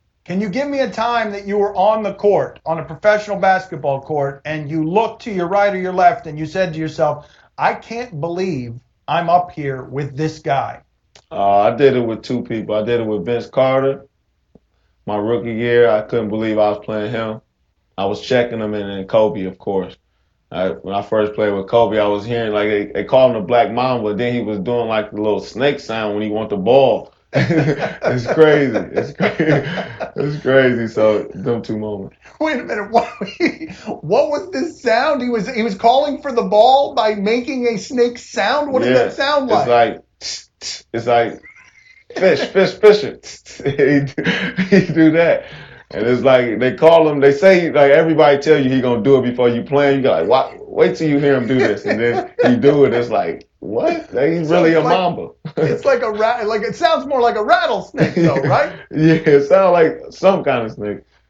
Crawford appeared on “Dan Le Batard is Highly Questionable” Wednesday and talked about his “oh wow” moments when he joined the NBA.
Click here to hear Crawford talk about Kobe